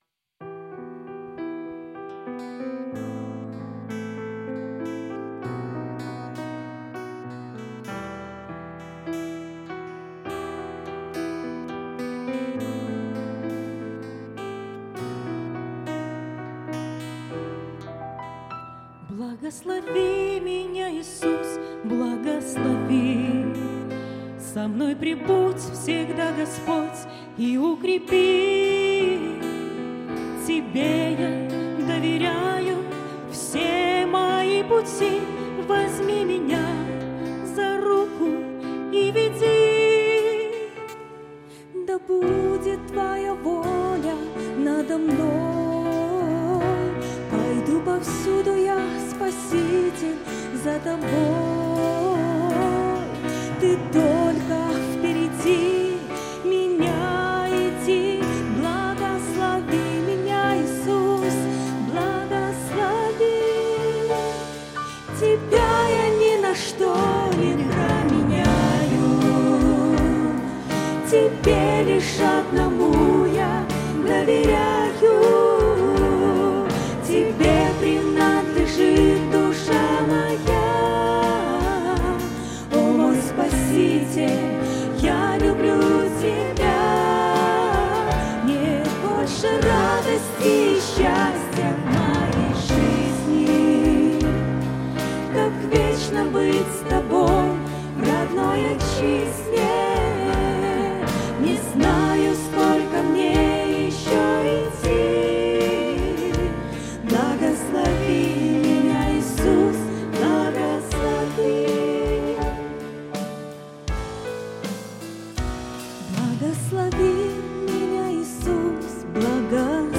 С энтузиазмом и свежими силами, в новом составе с октября 2017 года группа участвует в церковных богослужениях, постоянно стремясь к развитию и регулярно обновляя репертуар.
бас-гитара
вокал
гитара, вокал
ударные
клавиши, вокал